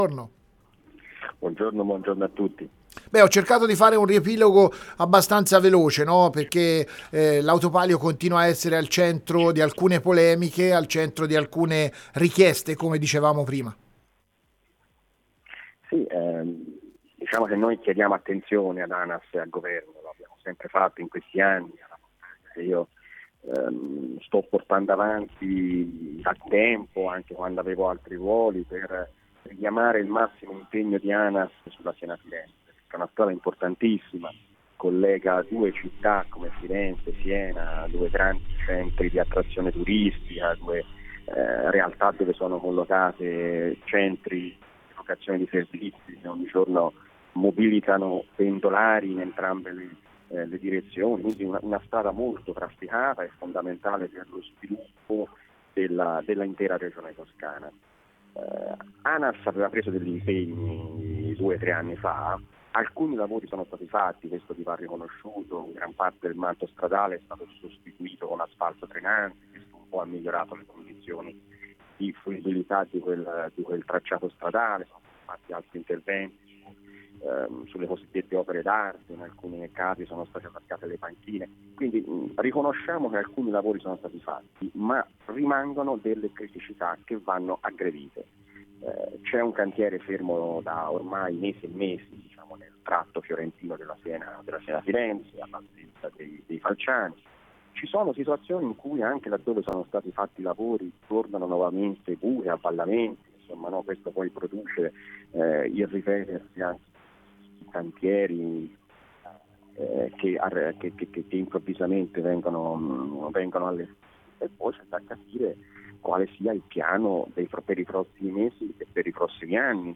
A spiegare la situazione ai nostri microfoni è stato lo stesso Bezzini, partendo dai lavori interrotti lungo il viadotto ai Falciani passando per le criticità che presenta l’asfalto dell’Autopalio per concludere con un appello al governo stesso, che fino ad ora non ha dichiarato comunicazioni in merito.